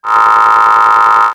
ihob/Assets/Extensions/RetroGamesSoundFX/Hum/Hum23.wav at master
Hum23.wav